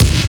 GRIND KICK.wav